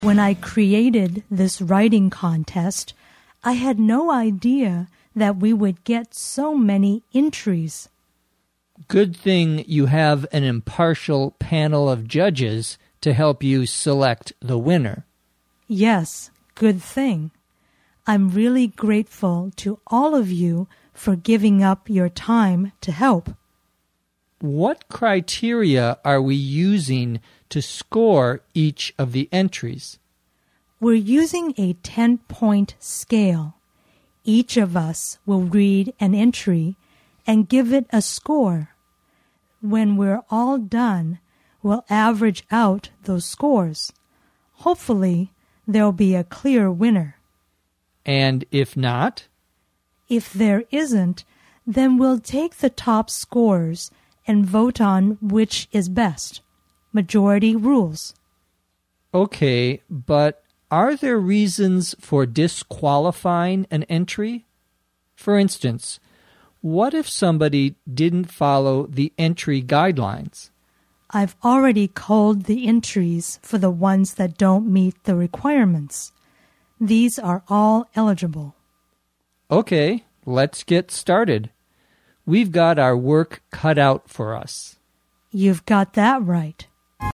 地道美语听力练习:文稿评分